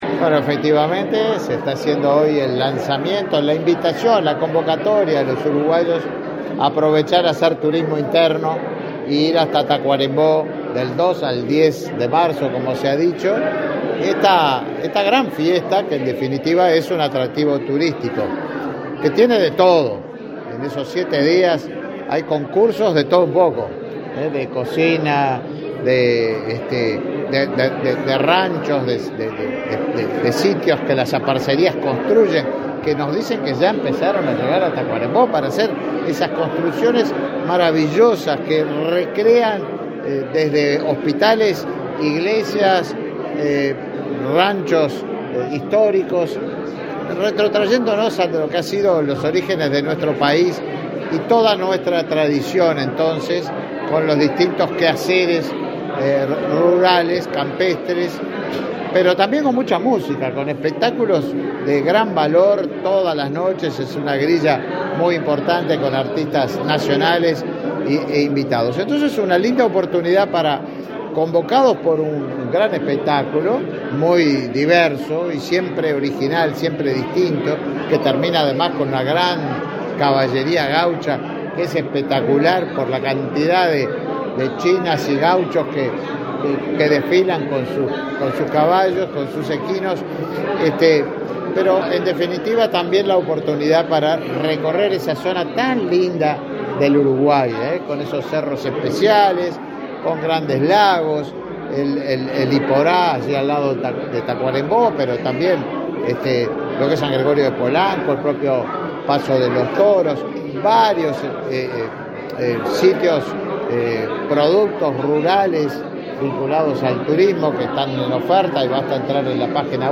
Declaraciones a la prensa del ministro de Turismo, Tabaré Viera
Declaraciones a la prensa del ministro de Turismo, Tabaré Viera 07/02/2024 Compartir Facebook X Copiar enlace WhatsApp LinkedIn Tras participar en el acto de lanzamiento de una nueva edición de la Fiesta de la Patria Gaucha, este 7 de febrero, el ministro de Turismo, Tabaré Viera, realizó declaraciones a la prensa.